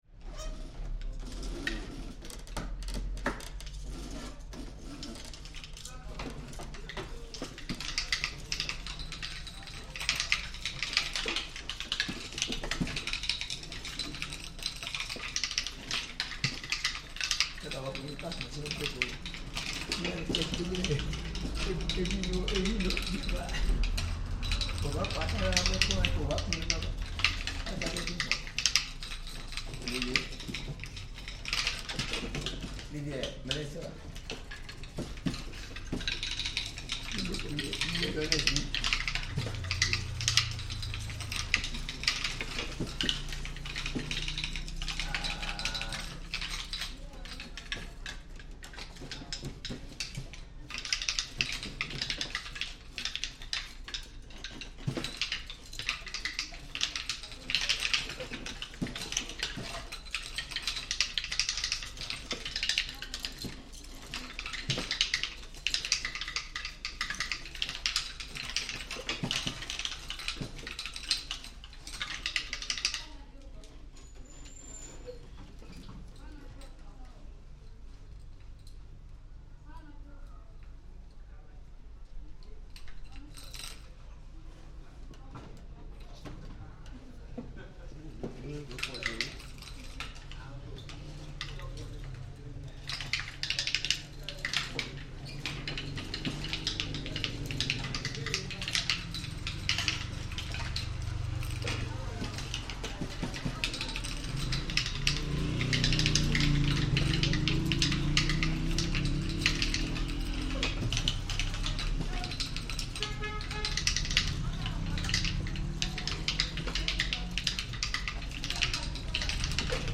Ewe Kente weaving by a group of two weavers in the Kpetoe community in Volta Region, Ghana.